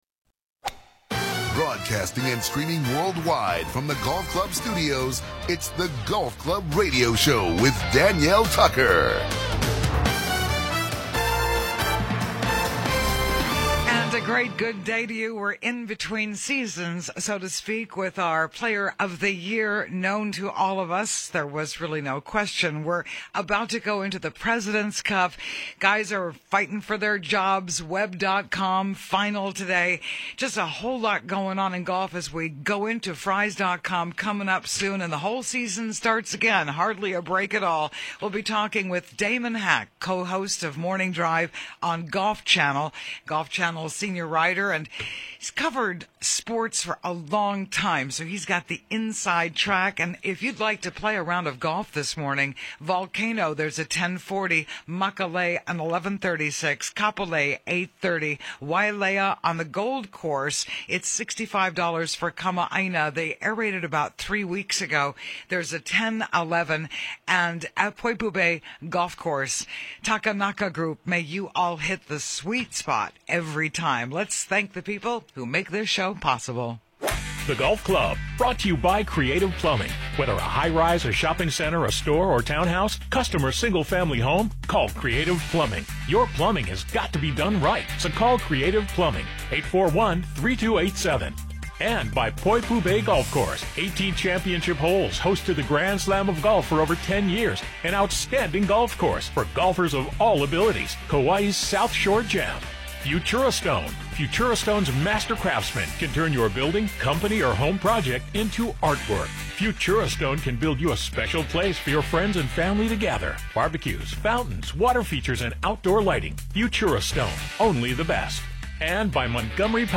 In the Clubhouse: Damon Hack: Golf Channel�s co-host of Morning Drive show.